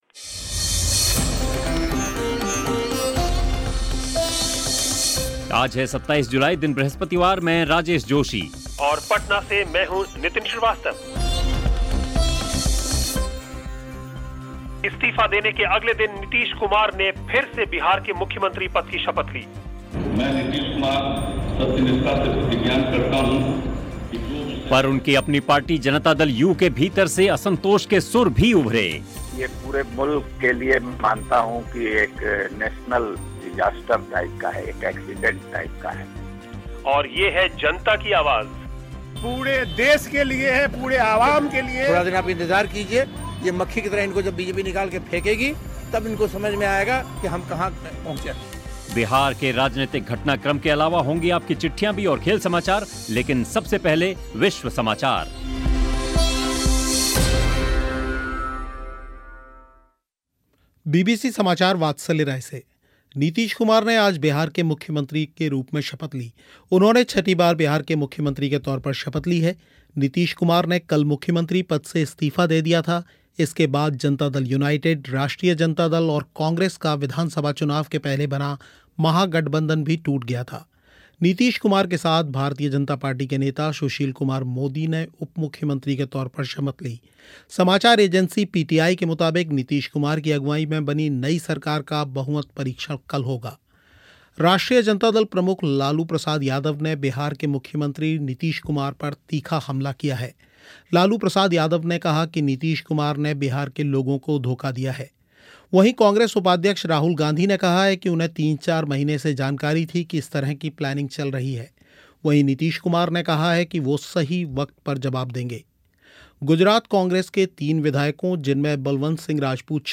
और सुनिएगा कि आम लोग क्या कहते हैं